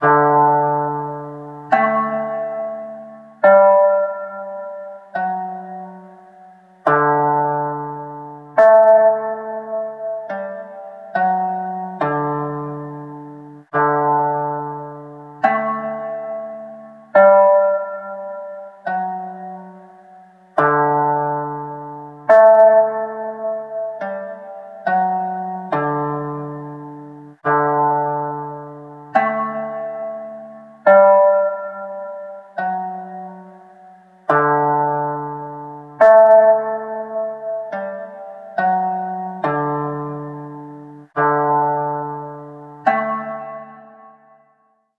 ★MUBERTで生成した音楽
なにか、不安な感じに聞こえますね
不協和音ですね。弾き始めの木琴みたいな
ぜんぜん、朝の爽やかな曲ではないですよね。